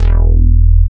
75.01 BASS.wav